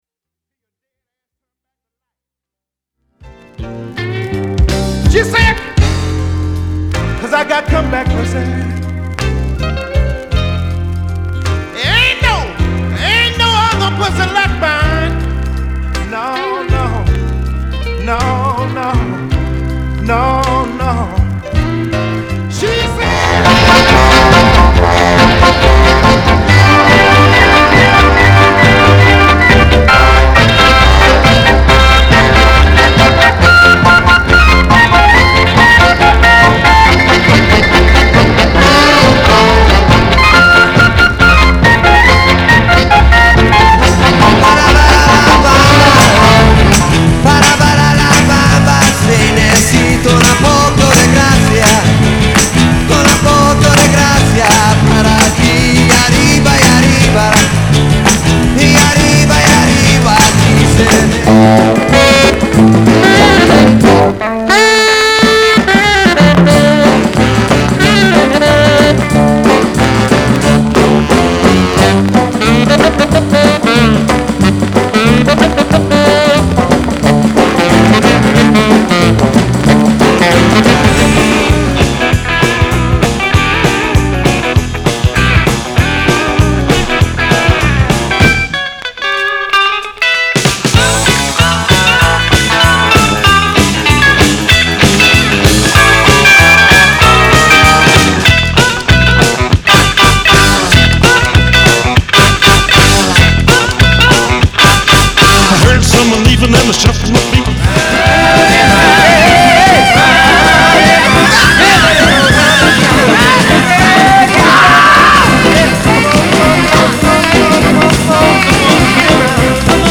類別 藍調
/盤質/両面やや傷あり/US PRESS